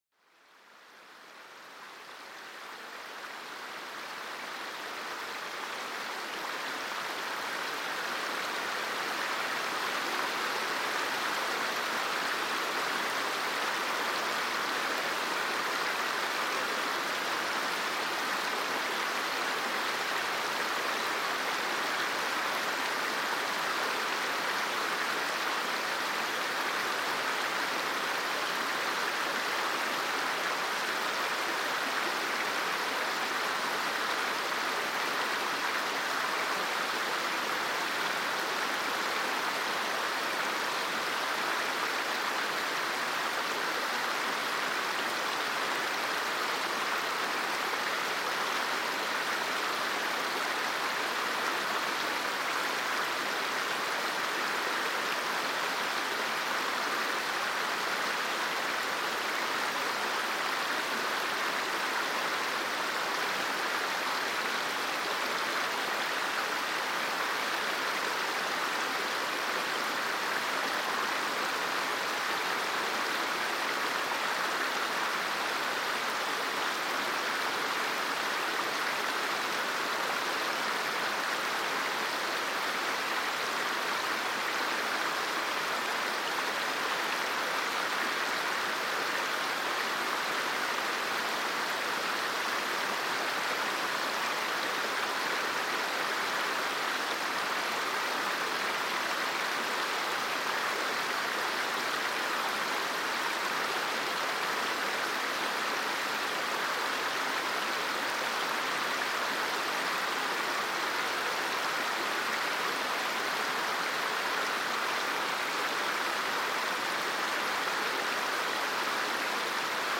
Apaisement des flots : le murmure d’une rivière pour une sérénité profonde